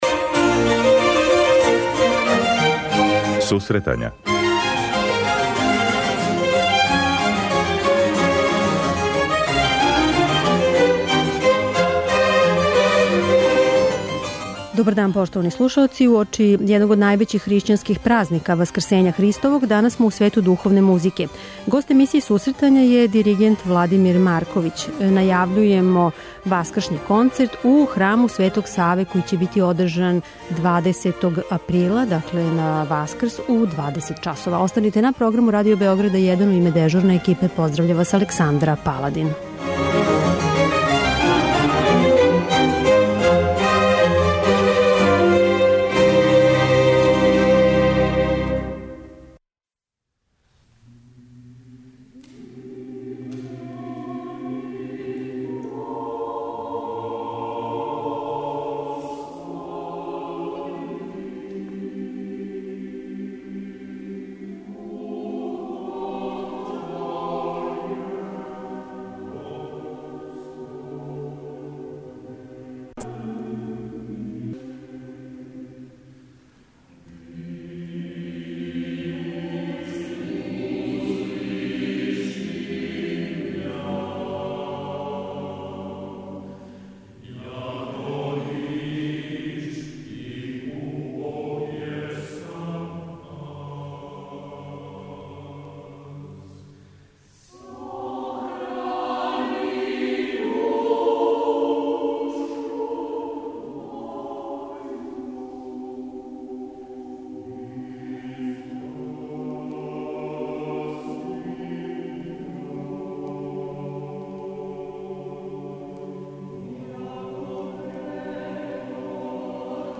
Уочи Ускрса, једног од највећих хришћанских празника, бићемо у свету духовне музике.